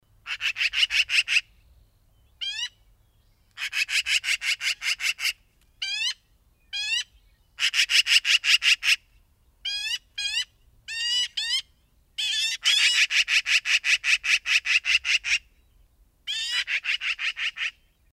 Вы можете слушать онлайн или скачать в формате mp3 её характерное стрекотание, карканье и другие варианты криков.
Крик болтливой сороки